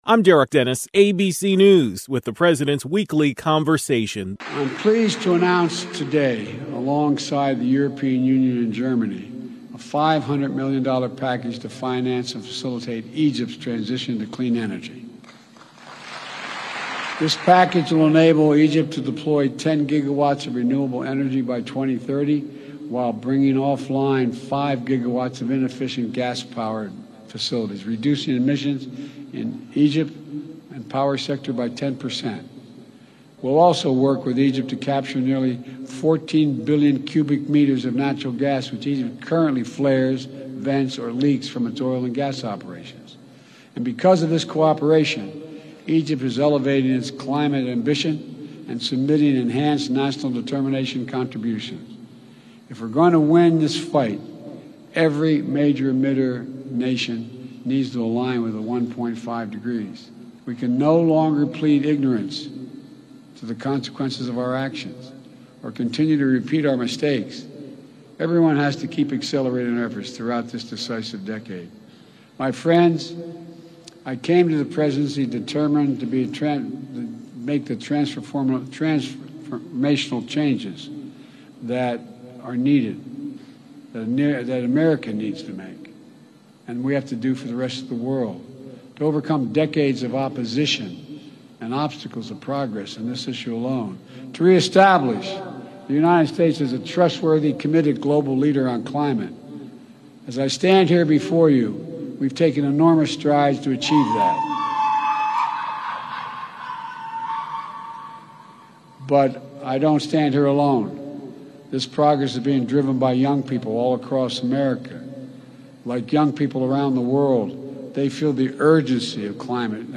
President Biden delivered remarks at the 27th Conference of the Parties to the Framework Convention on Climate Change (COP27) in Sharm el-Sheikh, Egypt.